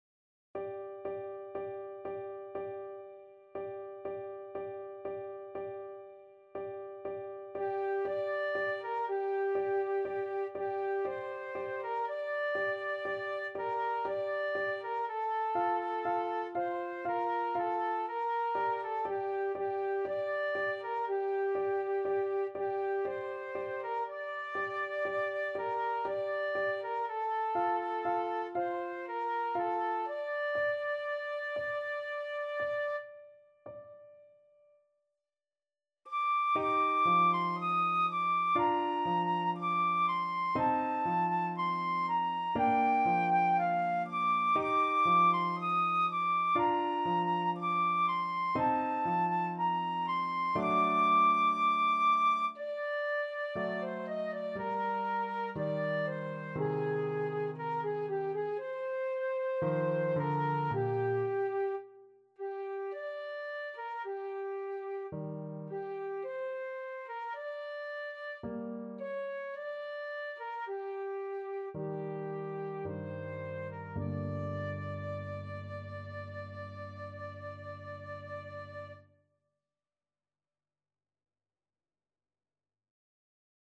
Classical Liszt, Franz La cloche sonne, S.238 Flute version
3/4 (View more 3/4 Music)
G minor (Sounding Pitch) (View more G minor Music for Flute )
Andante sostenuto =60
Classical (View more Classical Flute Music)